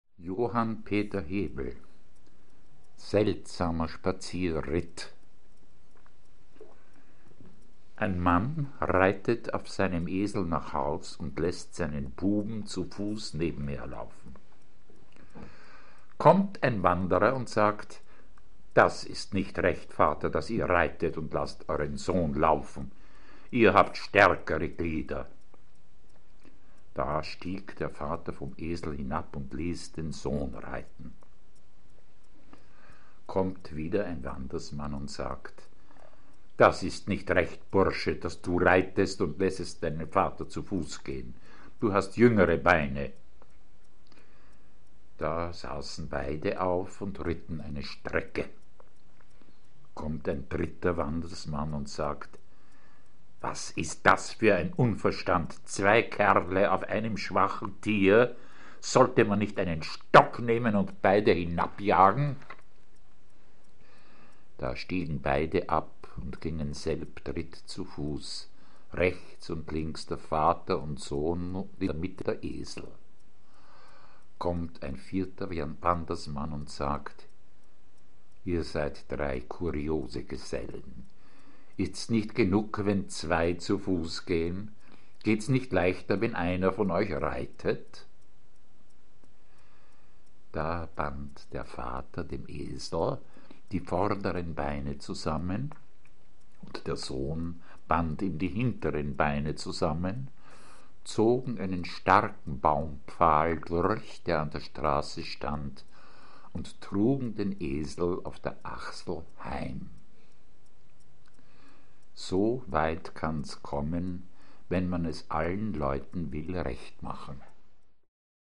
Werner Bergengruen - Literatur zum Anhören
seltsamer_spazierritt_kalendergeschichte_j_p_hebel.mp3